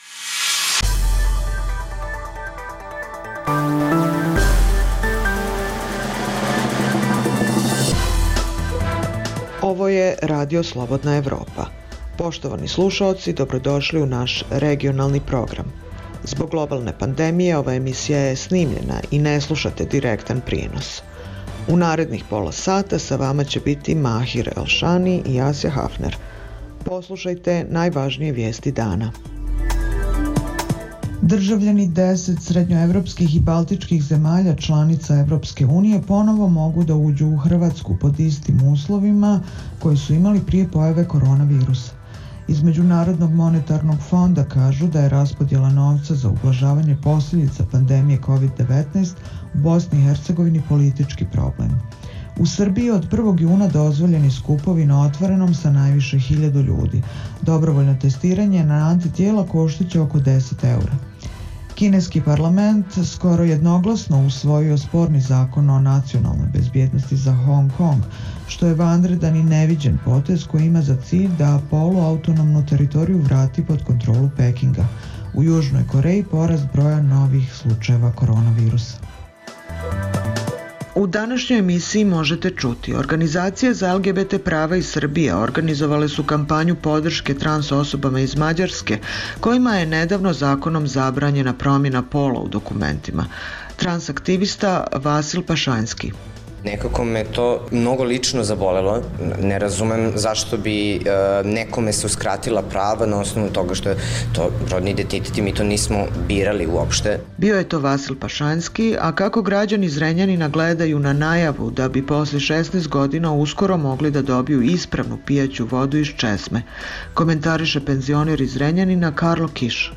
Zbog globalne pandemije, ova je emisija unapred snimljena i nije direktan prenos U današnjoj emisiji možete čuti: … organizacije za LGBT prava iz Srbije organizovale su kampanju podrške trans osobama iz Mađarske kojima je nedavno zakonom zabranjena promena pola u dokumentima. Kako građani Zrenjanina gledaju na najavu da bi posle 16 godina uskoro mogli da dobiju ispravnu pijaću vodu iz česme.